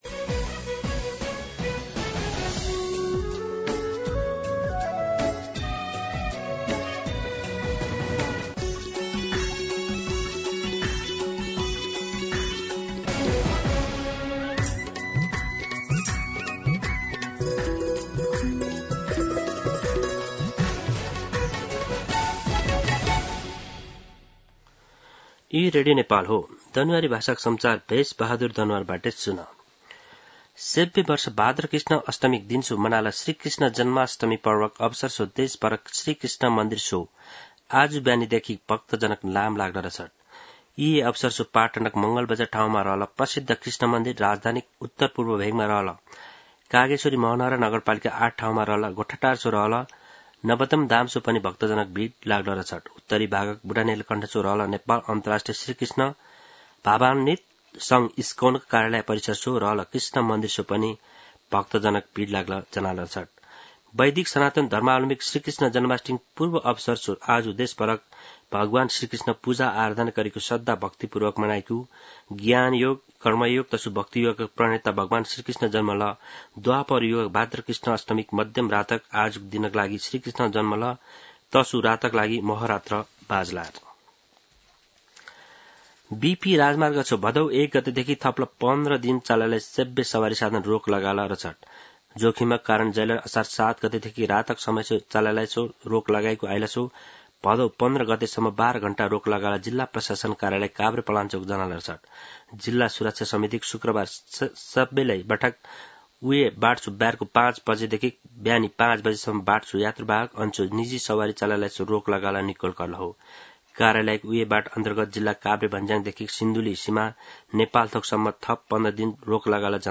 दनुवार भाषामा समाचार : ३१ साउन , २०८२
Danuwar-News-04-31.mp3